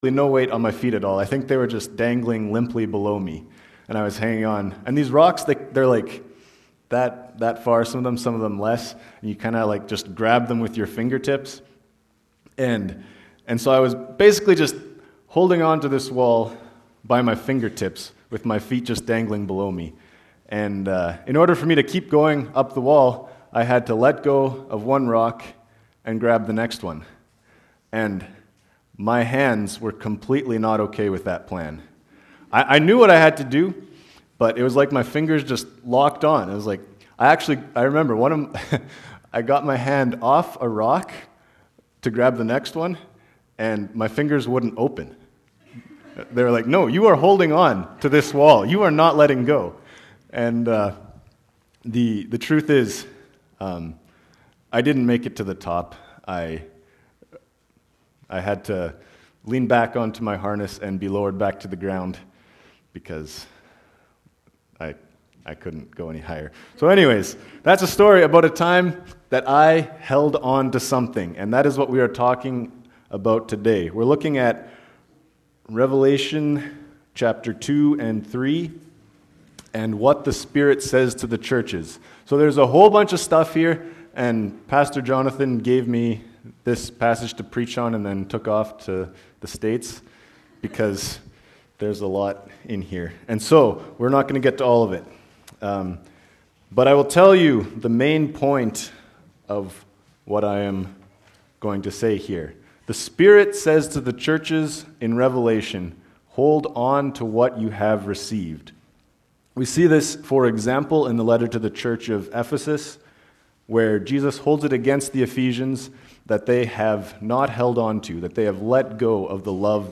Posted in Sermons .